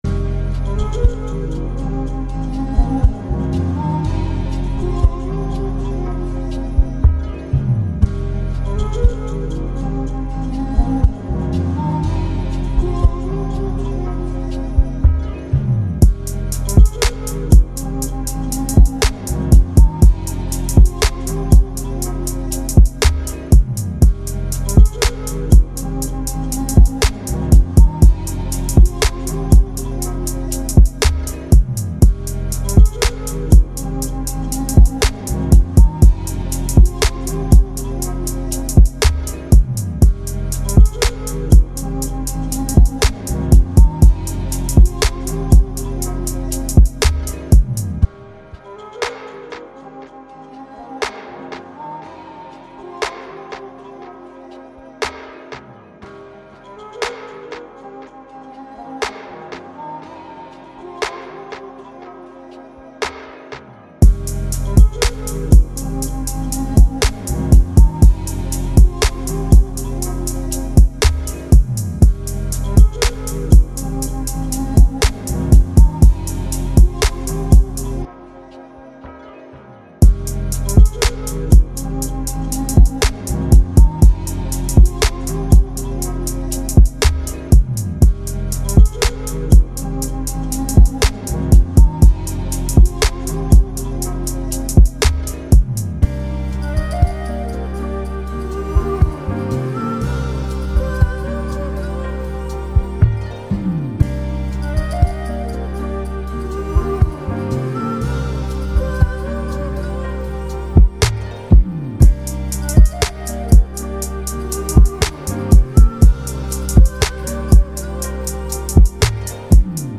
120 BPM.
hip hop rap beat instrumental lofi chillhop sample trap